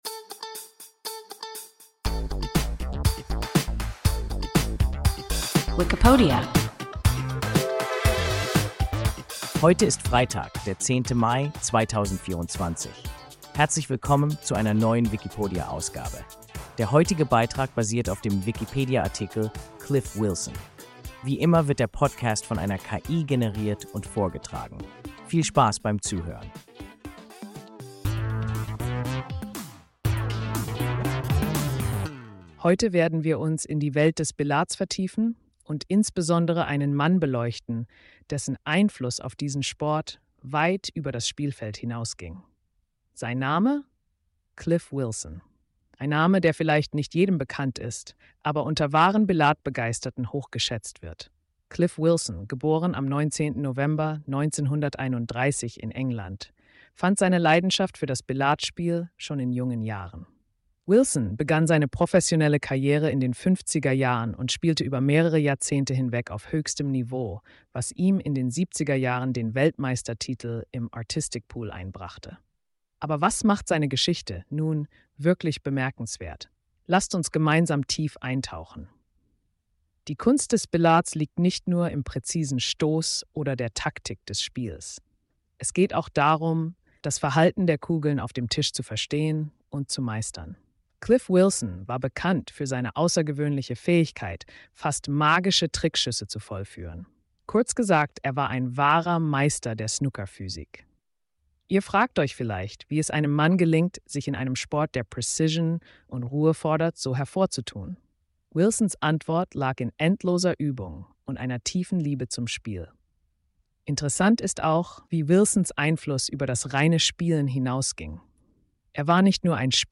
Cliff Wilson – WIKIPODIA – ein KI Podcast